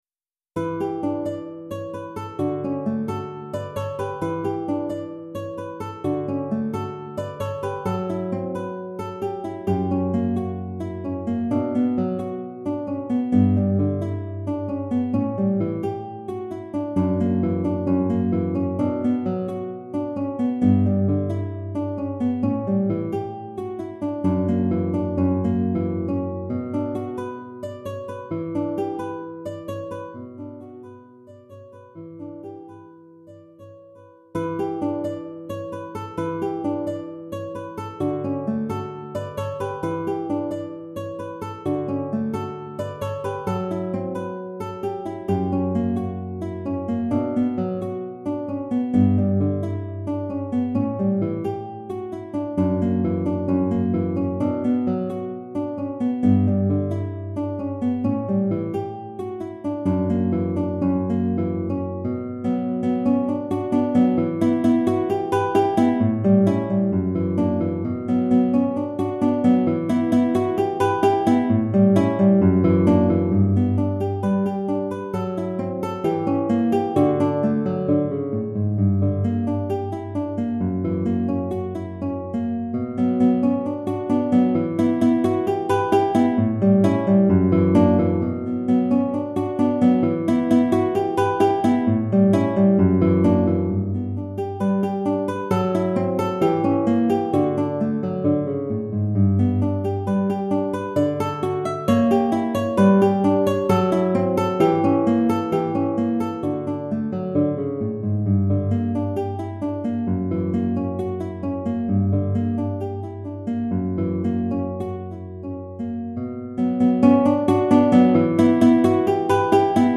Répertoire pour Guitare